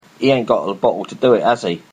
Examples of Cockney English
//i ʌɪn gɒʔ ðə ˈbɒʔəl tə dʉː ɪʔ/æz i//
Notice the glottal stop between vowels in bottle and h-dropping in has he.
04_Cockney_He_aint_got_the_bottle.mp3